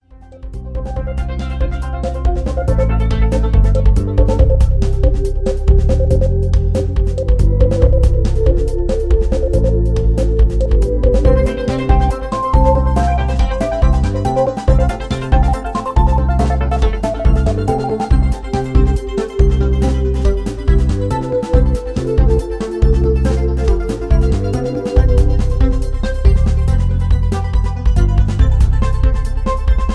Soft Electro Chill Out Loop
Tags: synth, tender